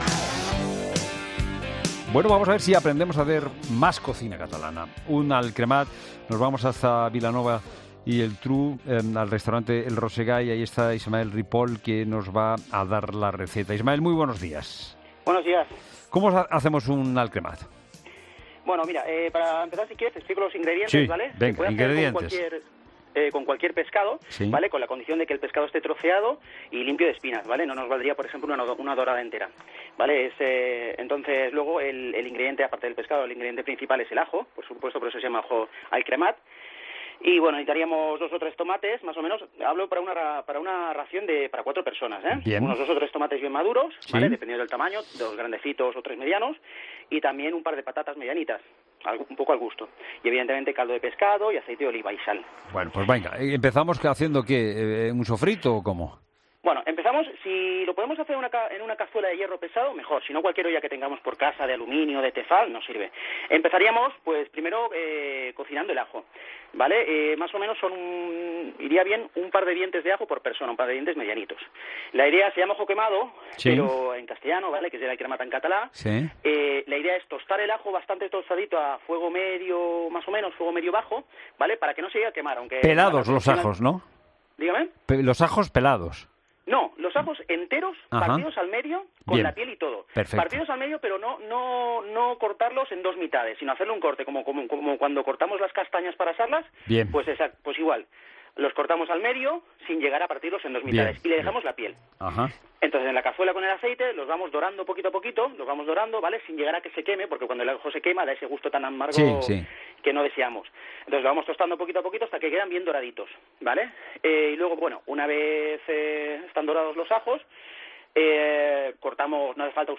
AUDIO: Un cocinero relata a los oyentes de 'La Mañana' como hacer una genuina All Cremat de Catalunya.